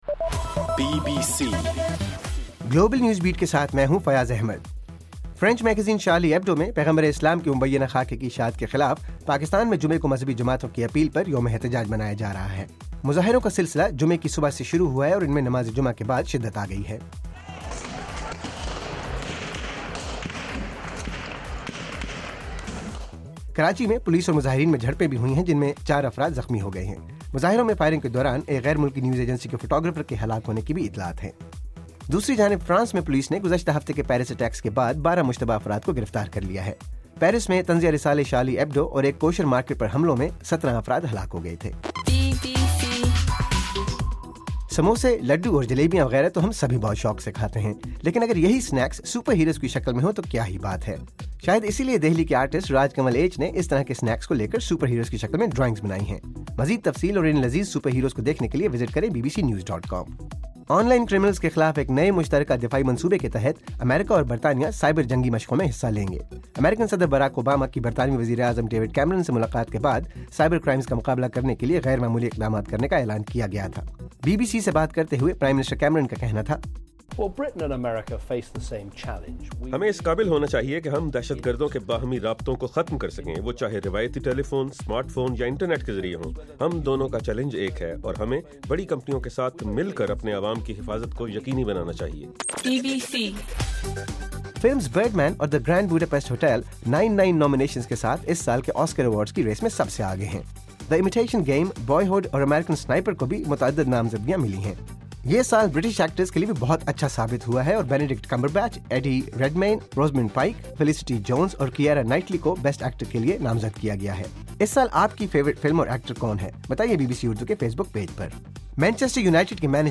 جنوری 16: رات 9 بجے کا گلوبل نیوز بیٹ بُلیٹن